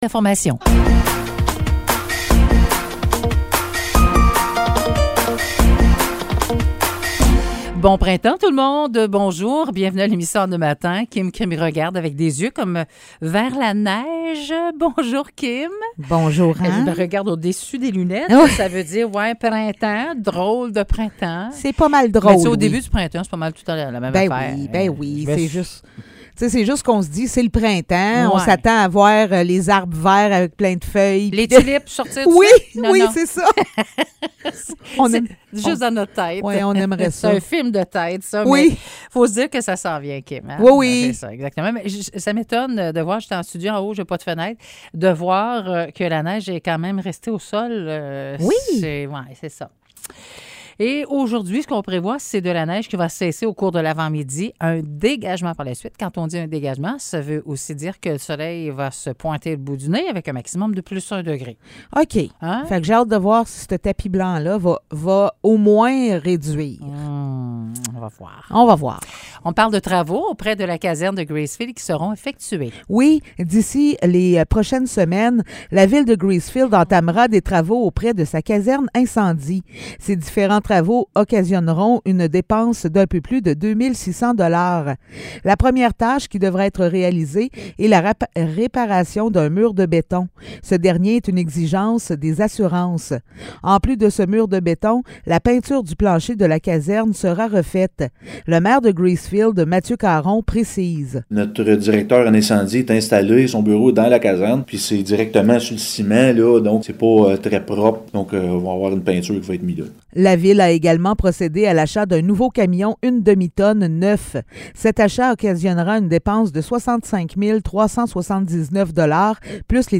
Nouvelles locales - 21 mars 2023 - 9 h